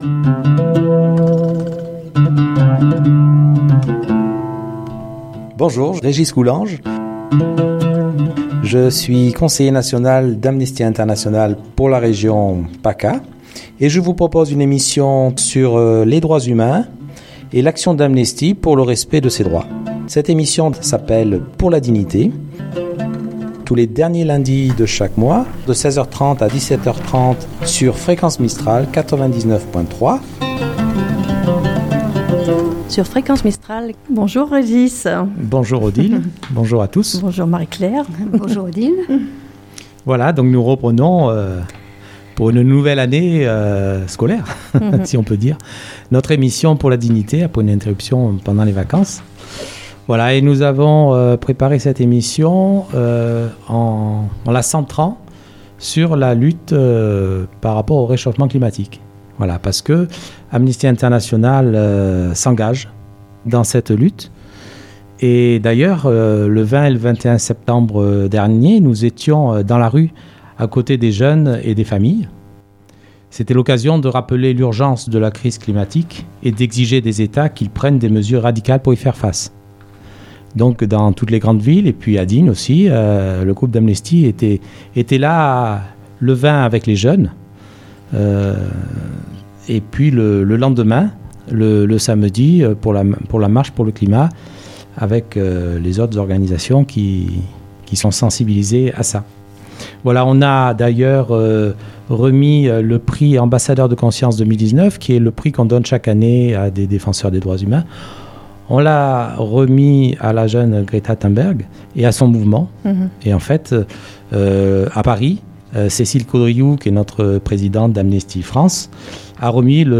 La Chronique « tribunal Monsanto »